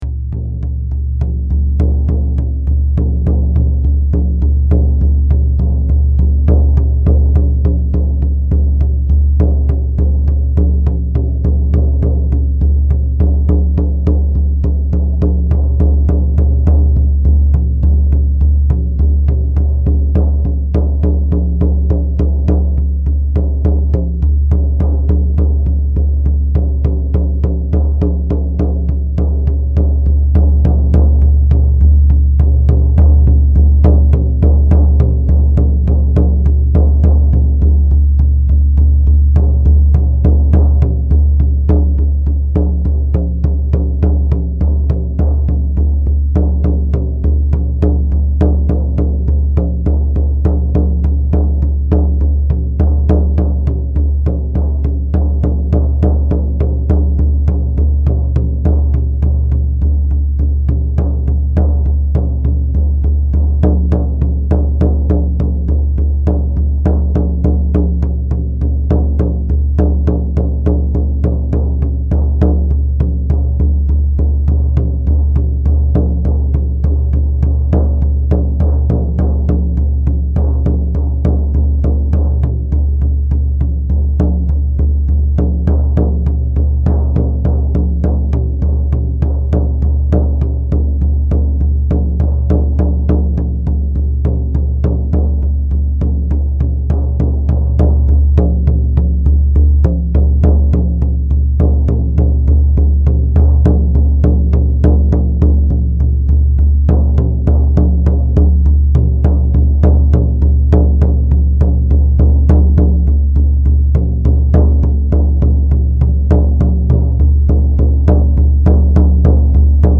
Здесь вы найдете бубны, горловое пение, заклинания, звуки огня и ветра – всё для медитации, релаксации или творческого вдохновения.
Ритмичные удары в шаманский бубен